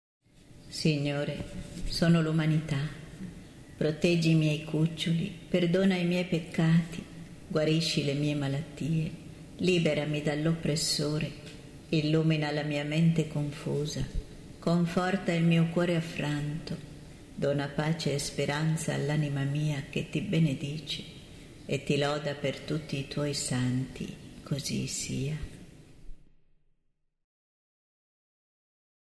n. 2 preghiera mp3 – Kyrie eleison canto mp3